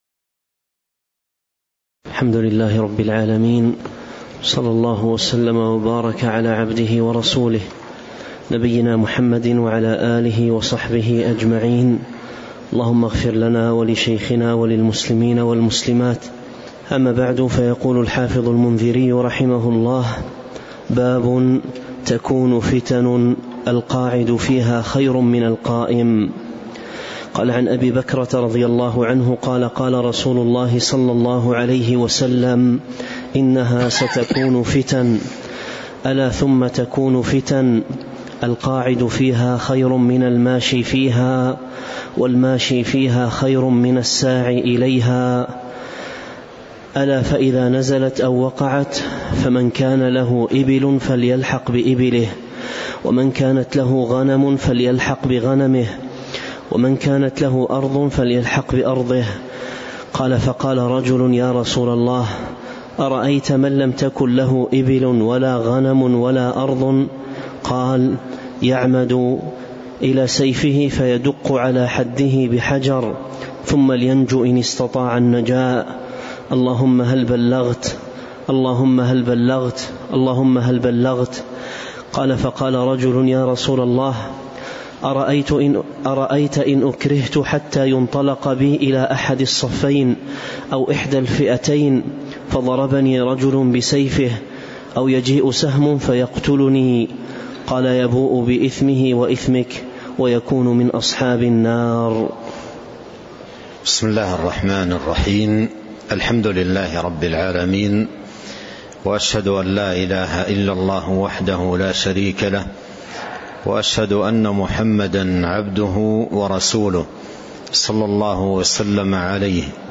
تاريخ النشر ٥ صفر ١٤٤٤ هـ المكان: المسجد النبوي الشيخ